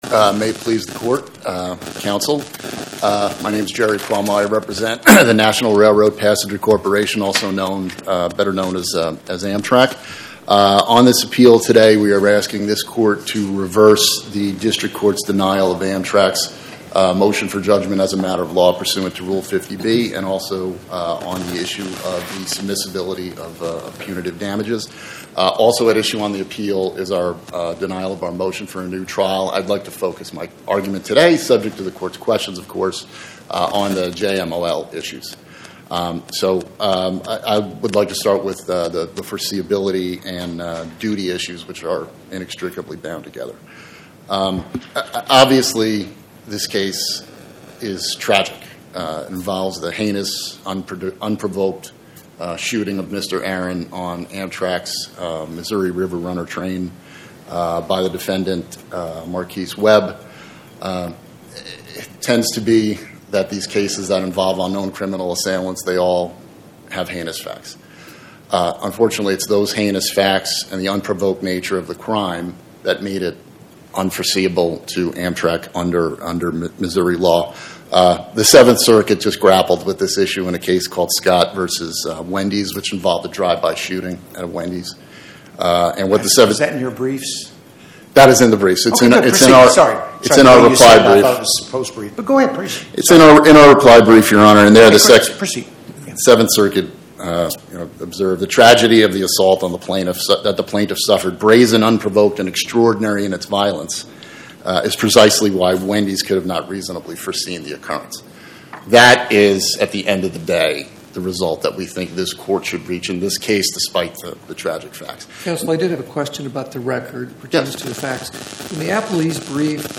Oral argument argued before the Eighth Circuit U.S. Court of Appeals on or about 09/17/2025